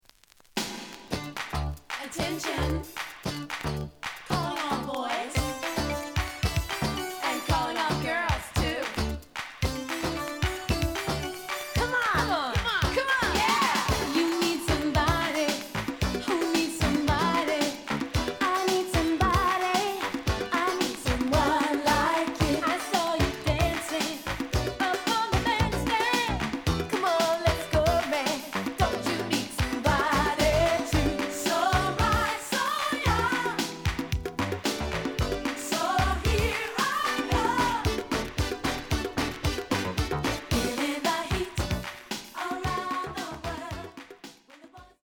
The audio sample is recorded from the actual item.
●Genre: Disco
●Record Grading: VG~VG+ (傷はあるが、プレイはおおむね良好。Plays good.)